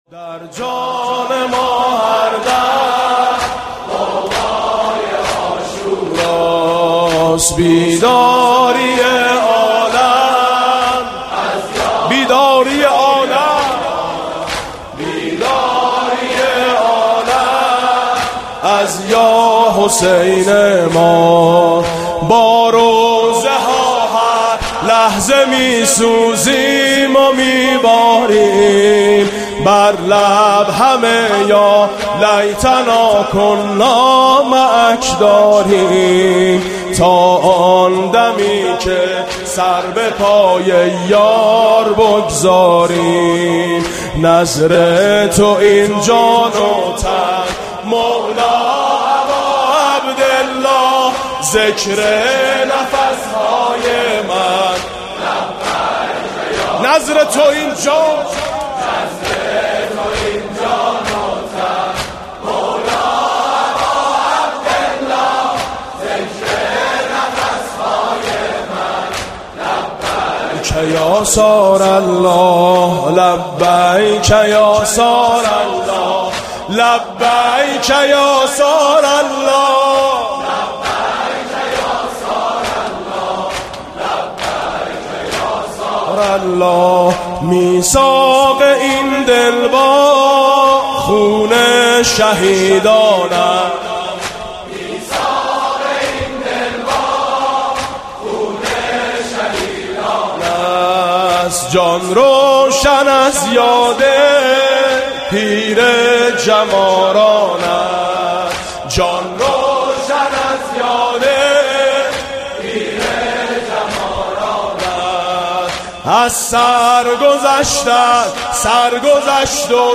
مجموعه مراسم میثم مطیعی در شب هشتم محرم 93
از خوشی های جهان هیات تو ما را بس (واحد)